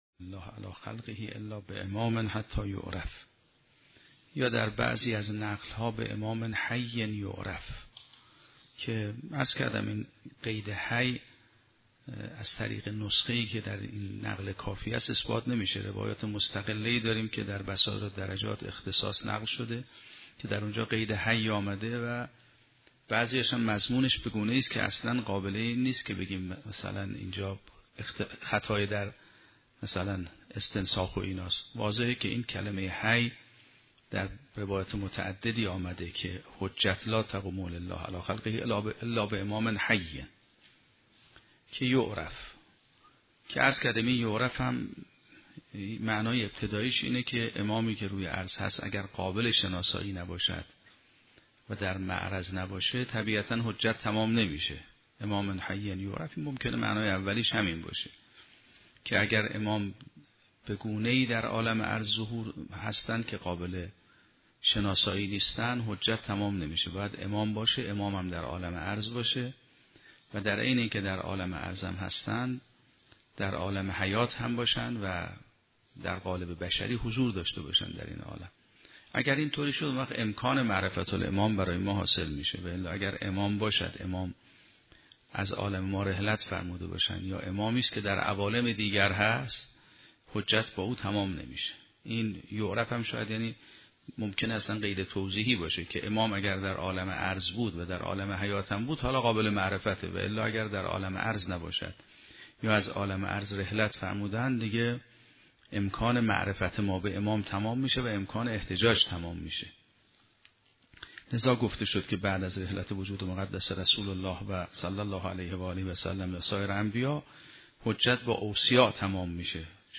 شرح و بررسی کتاب الحجه کافی توسط آیت الله سید محمدمهدی میرباقری به همراه متن سخنرانی ؛ این بخش : اتمام حجت با معرفت امام و شئون آن - شرح صحیحه ابان بن تغلب و معانی محتمله در حدیث